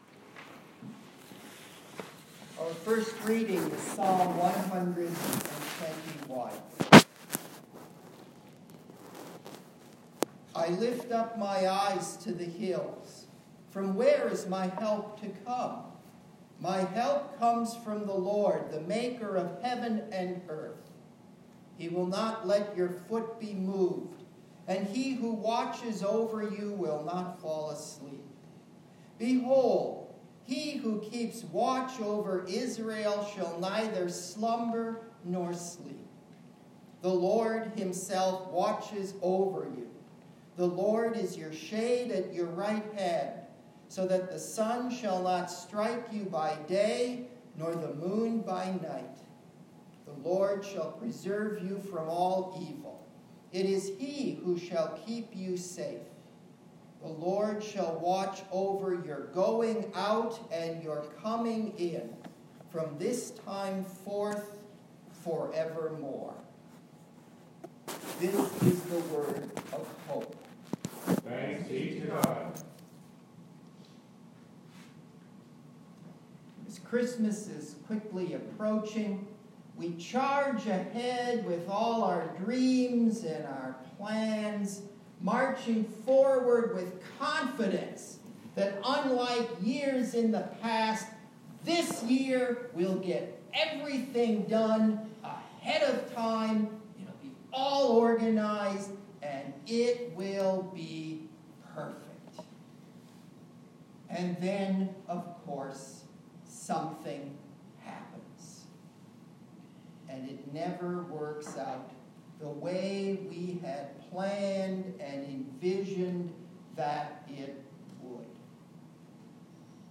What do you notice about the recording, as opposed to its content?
Last Sunday, we celebrated our annual Blue Christmas Service.